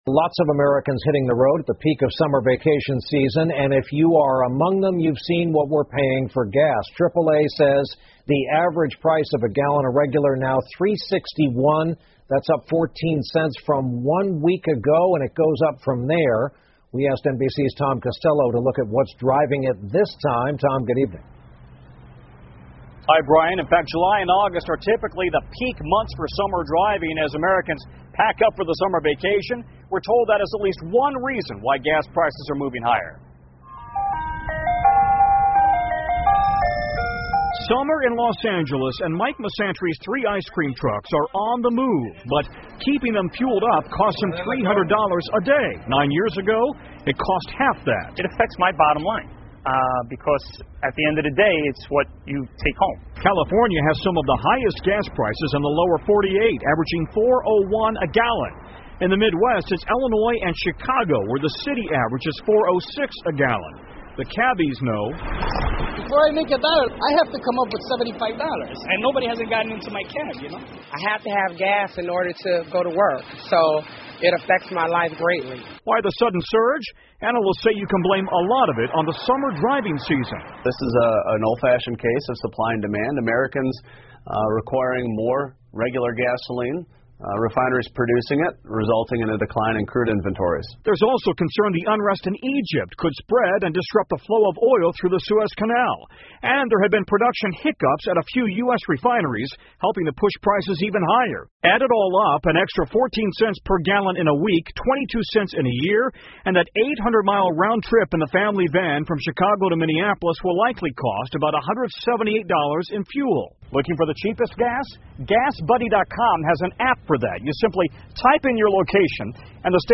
NBC晚间新闻 美国汽油价格上涨 听力文件下载—在线英语听力室